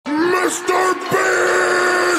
Memes
Mr Beast Scream